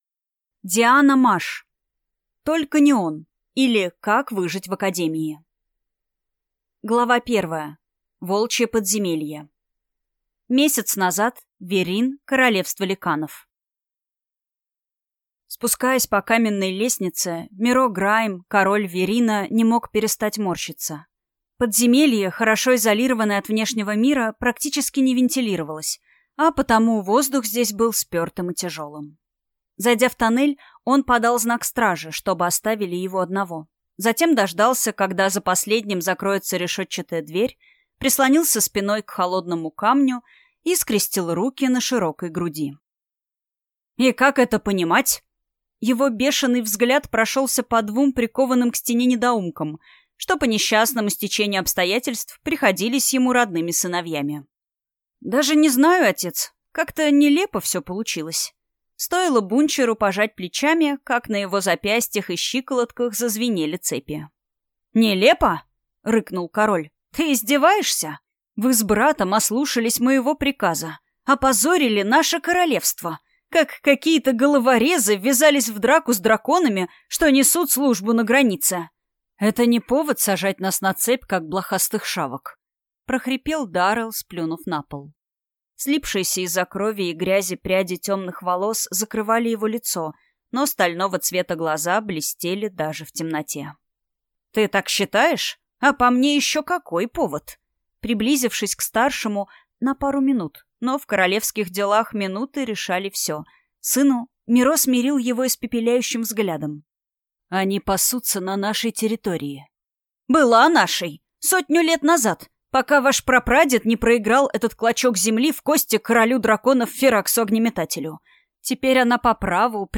Аудиокнига Только не он! или Как выжить в академии?